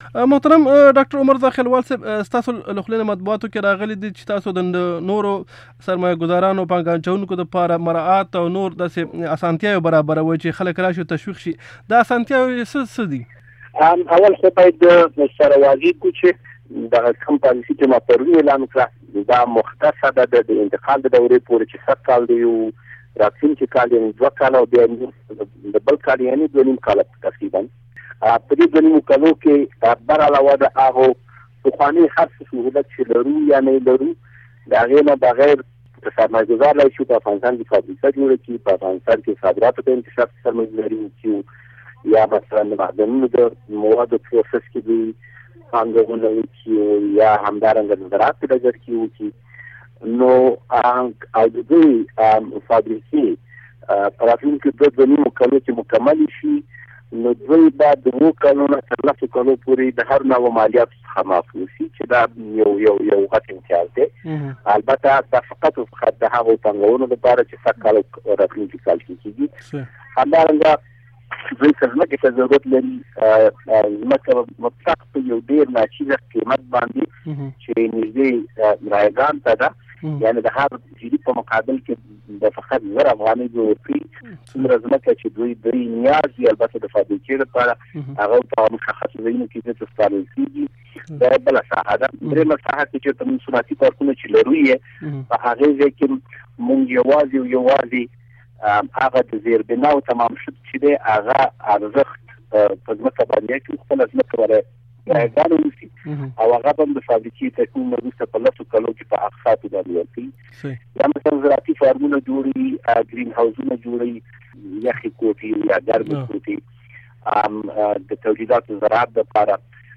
مرکې
zakhilwal interview